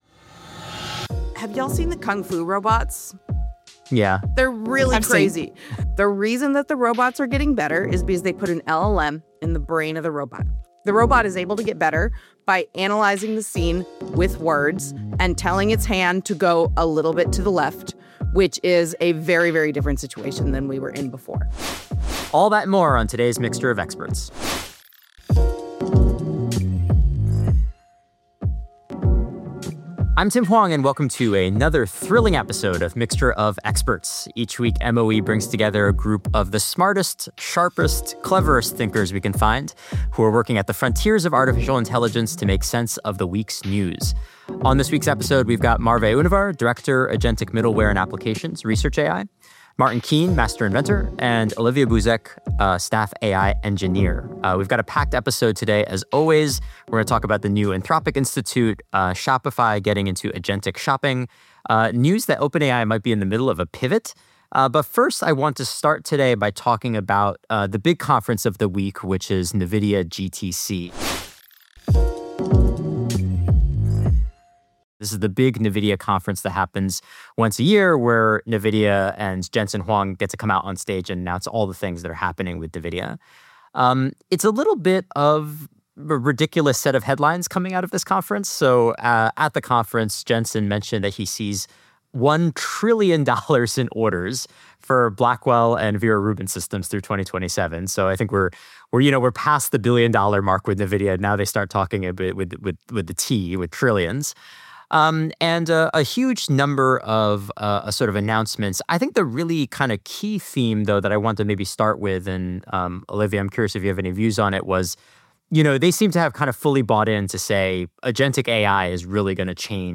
The opinions expressed in this podcast are solely those of the participants and do not necessarily reflect the views of IBM or any other organization or entity.